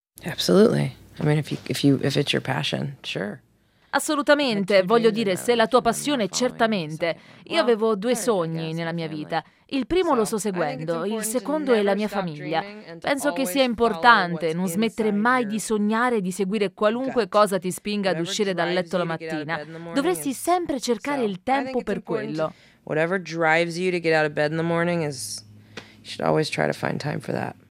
“Over Voice” per la sua perfetta dizione